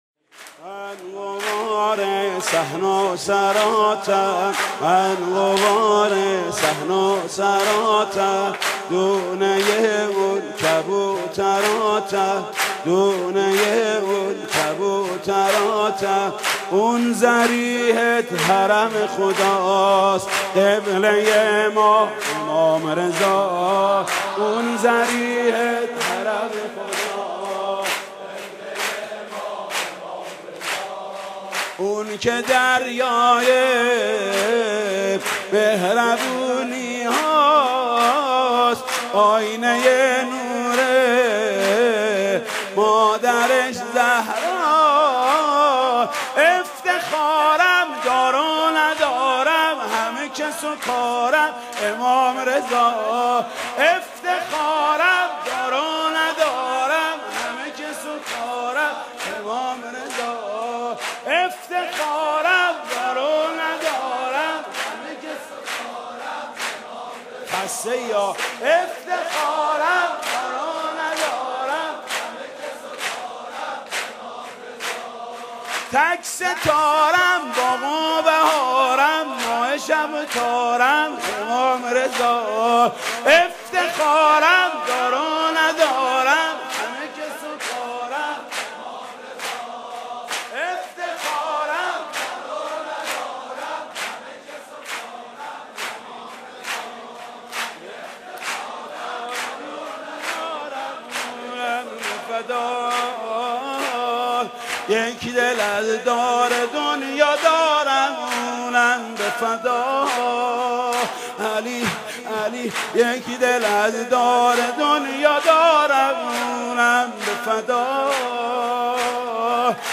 مولودی حاج محمود کریمی